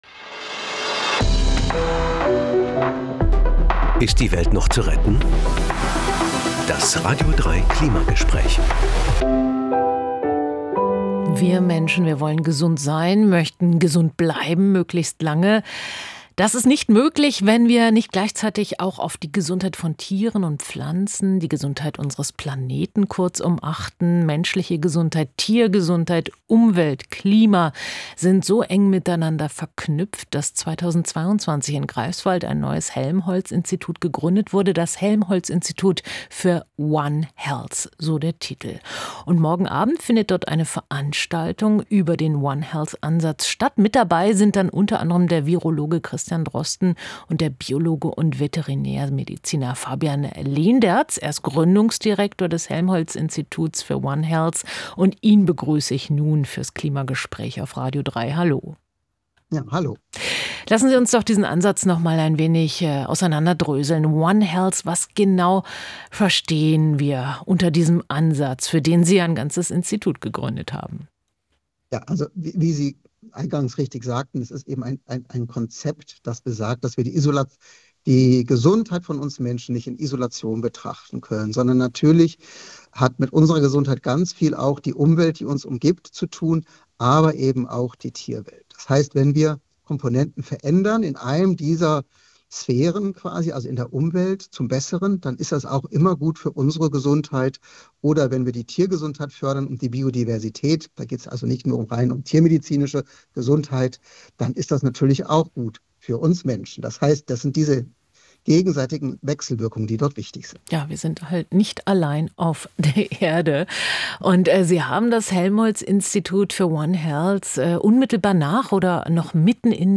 sprechen wir vorab im radio3 Klimagespräch.